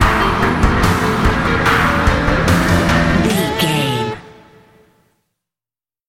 Thriller
Aeolian/Minor
synthesiser
drum machine
electric guitar
tension
ominous
dark
suspense
haunting
creepy
spooky